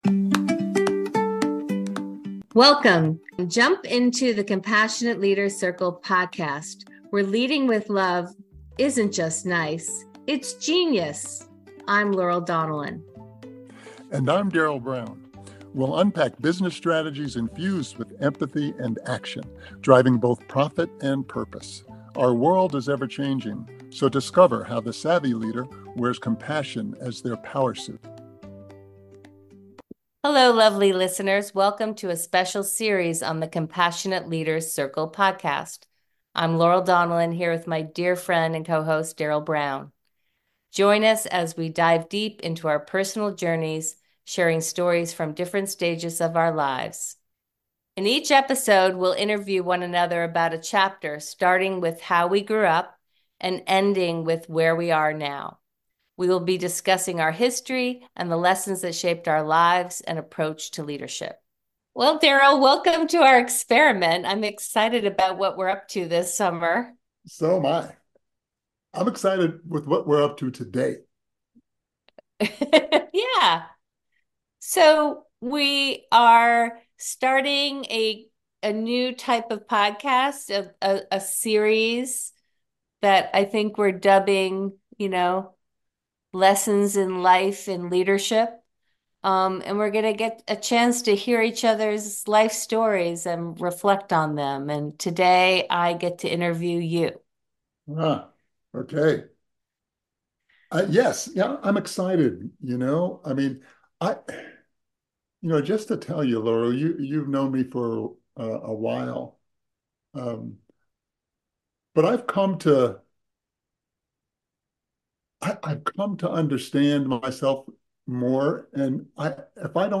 Tune in for a warm, intimate conversation that will inspire and uplift, reminding us all of the enduring power of compassion.